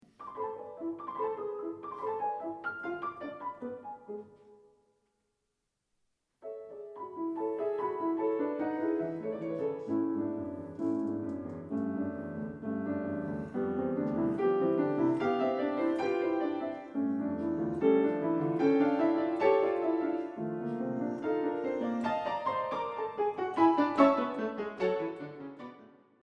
To continue, listen to the left hand, the lower part at 0.06 and forward. The little four notes are there constantly…